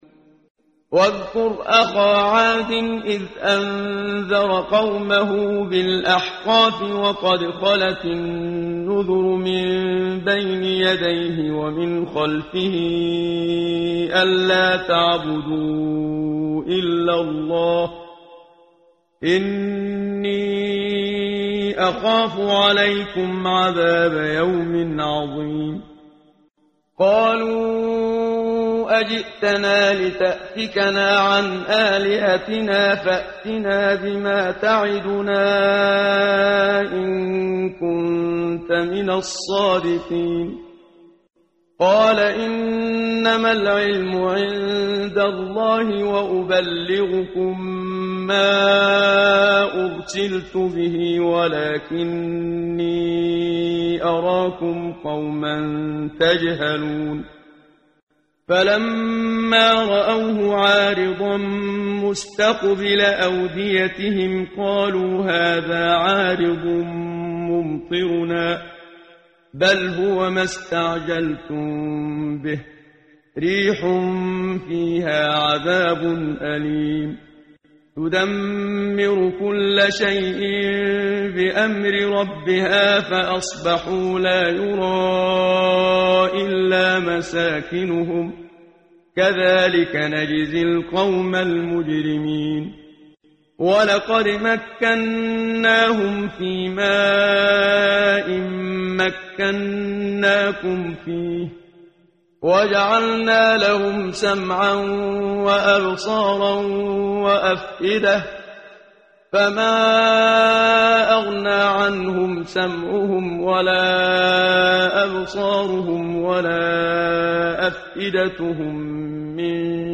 قرائت قرآن کریم ، صفحه 505، سوره مبارکه «الاحقاف» آیه 21 تا 28 با صدای استاد صدیق منشاوی.